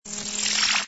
ui_begin_scan.wav